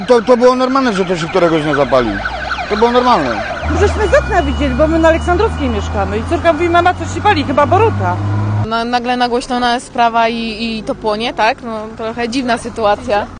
Posłuchaj: Nazwa Plik Autor Mieszkańcy Zgierza o pożarze audio (m4a) audio (oga) Ministerstwo środowiska zwraca uwagę na coraz większą liczbę pożarów składowisk odpadów.